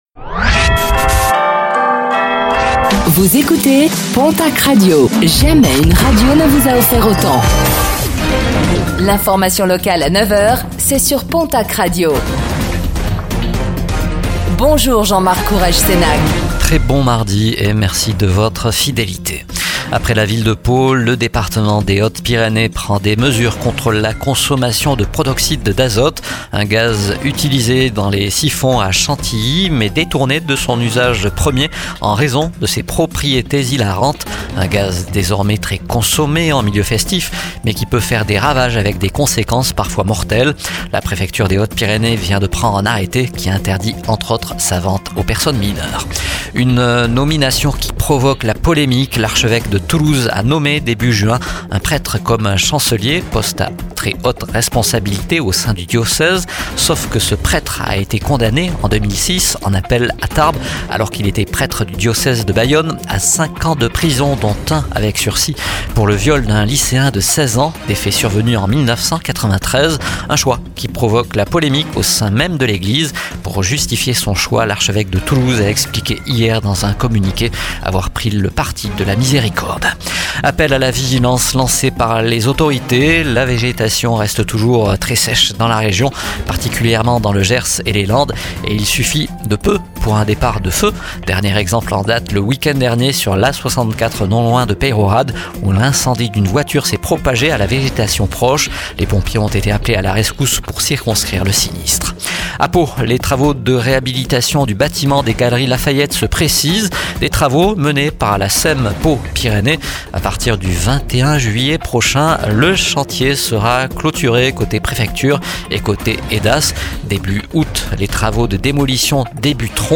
Réécoutez le flash d'information locale de ce mardi 08 juillet 2025